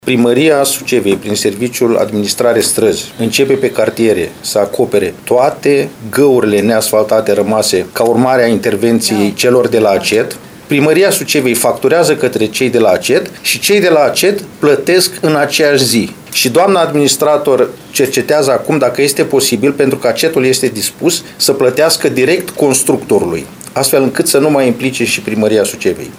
Consilierul local DAN CUȘNIR a declarat că lucrările vor fi efectuate de către constructorul care are contract de reparare și întreținere a străzilor, după care Primăria va factura către ACET.